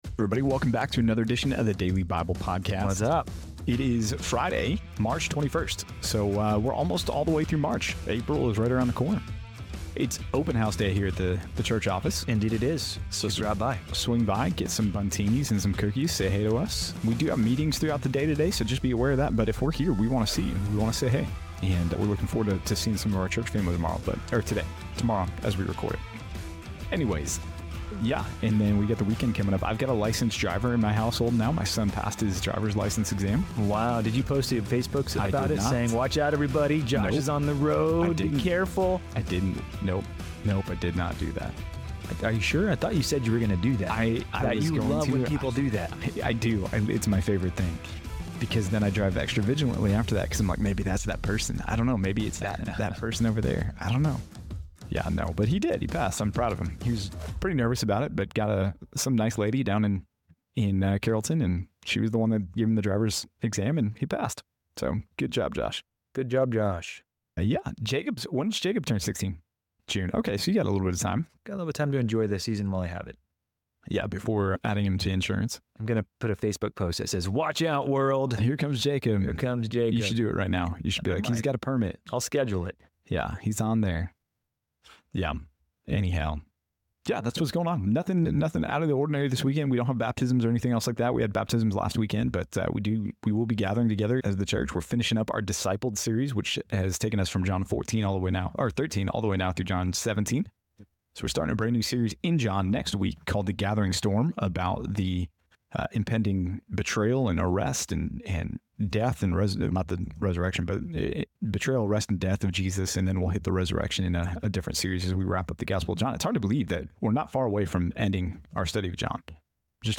In today's episode of the Daily Bible Podcast, the hosts welcome listeners on Friday, March 21st.